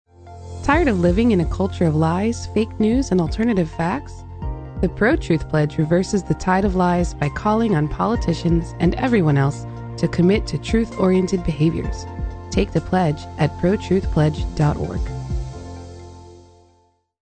PSAs for Podcasts and Radio Shows